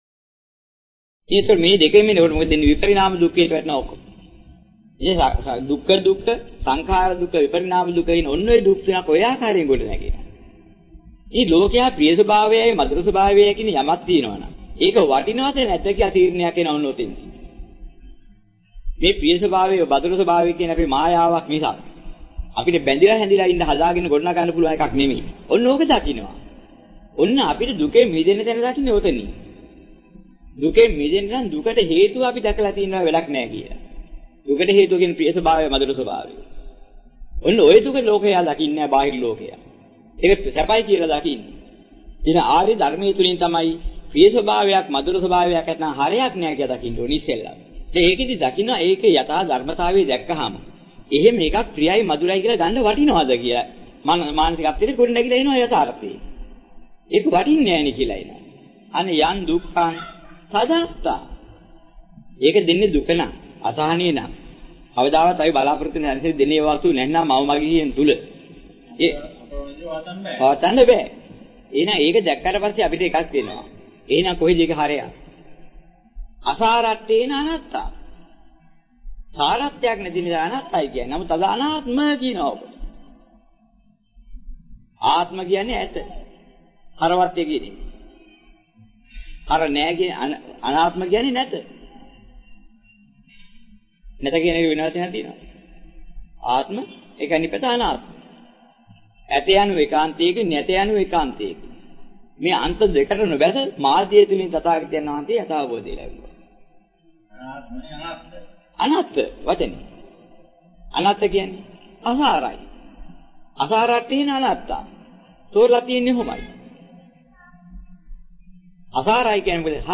වෙනත් බ්‍රව්සරයක් භාවිතා කරන්නැයි යෝජනා කර සිටිමු 41:58 10 fast_rewind 10 fast_forward share බෙදාගන්න මෙම දේශනය පසුව සවන් දීමට අවැසි නම් මෙතැනින් බාගත කරන්න  (17 MB)